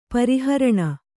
♪ pari haraṇa